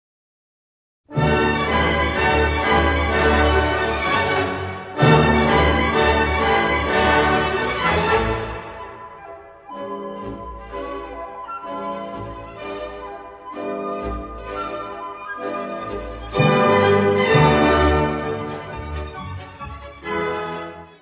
Peer Gynet, incidental music
: stereo; 12 cm + folheto
Área:  Música Clássica